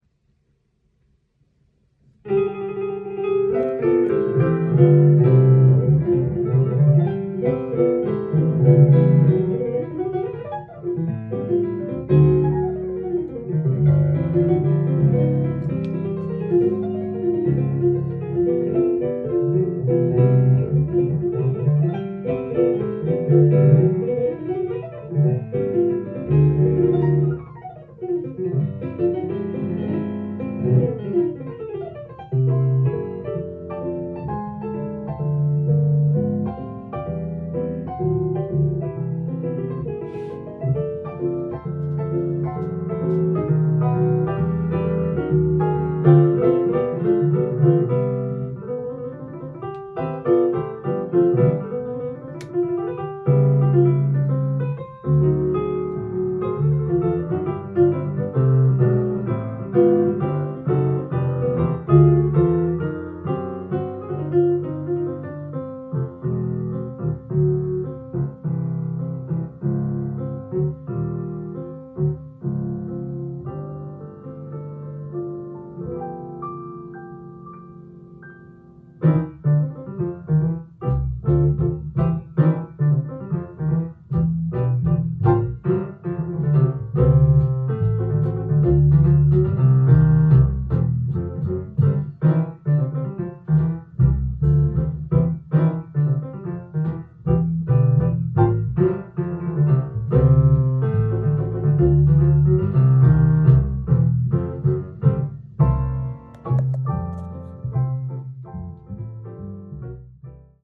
店頭で録音した音源の為、多少の外部音や音質の悪さはございますが、サンプルとしてご視聴ください。
端正で切れ味のいいパッセージと、音楽的なアイデアを持ち合わせたジャズ・ピアニスト